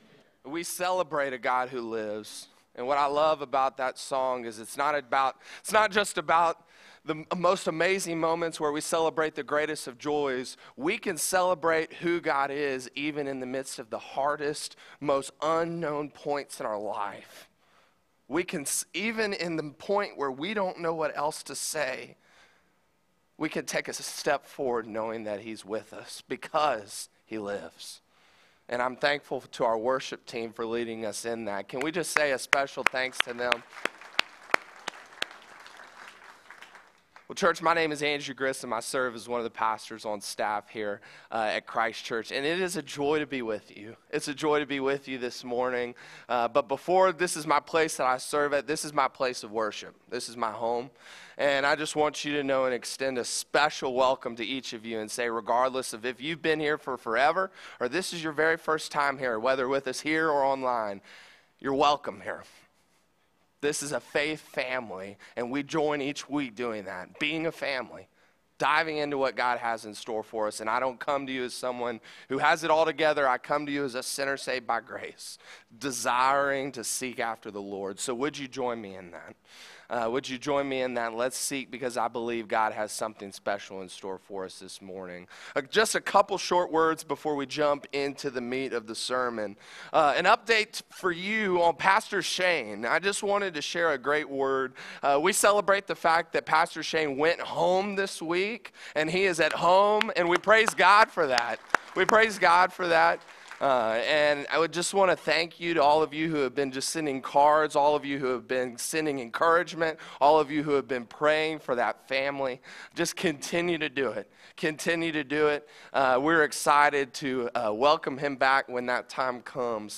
A message from the series "Flipside."